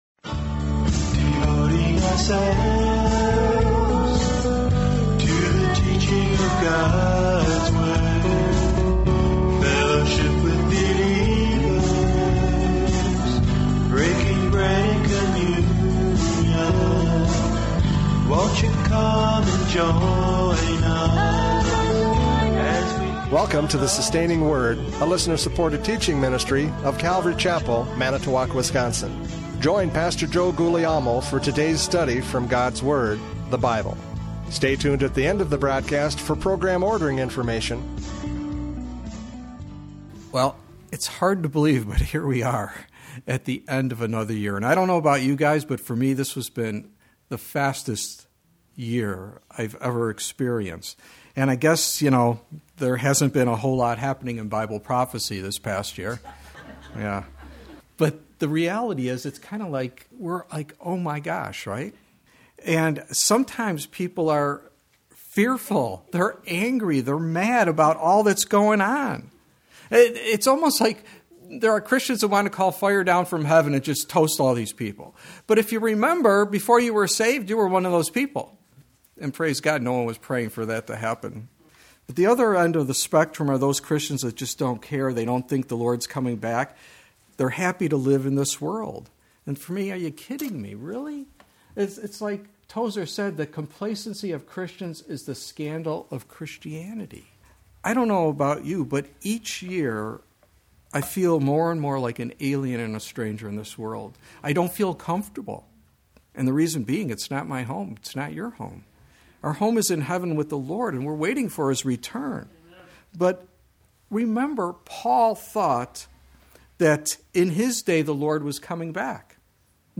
Radio Studies Service Type: Radio Programs « Christmas 2023 Luke 2:1-7 No Room!